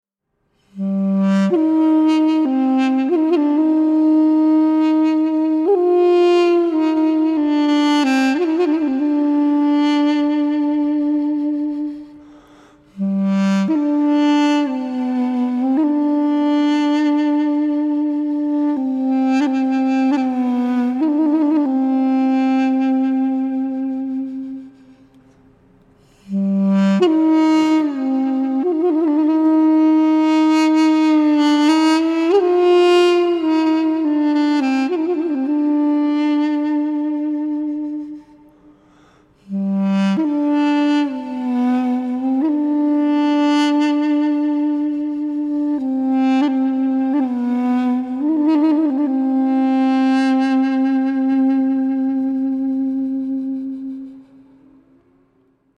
Дудук Bb
Тональность: Bb
Армянский дудук, изготовлен из абрикосового дерева.